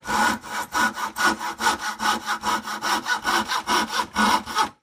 in_copingsaw_sawing_01_hpx
Coping saw cuts various pieces of wood. Tools, Hand Wood, Sawing Saw, Coping